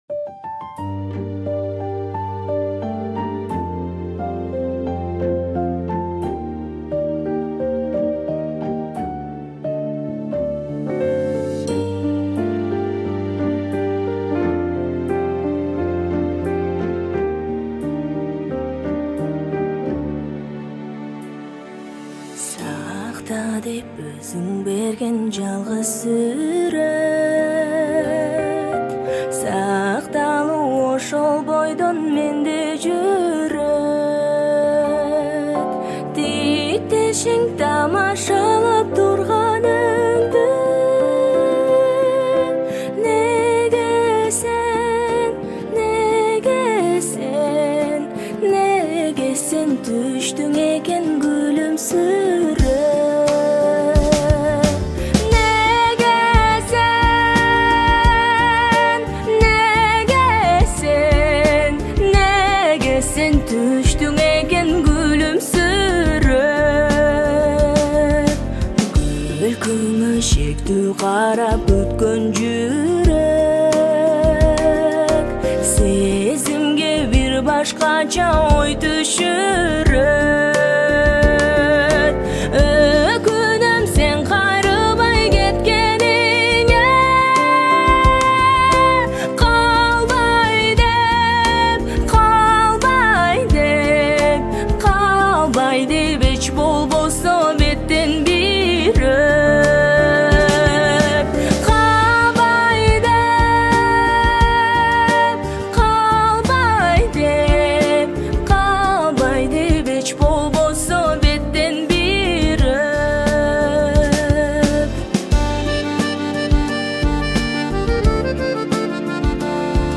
• Киргизские песни